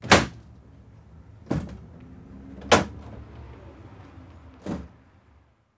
ambdooropen.wav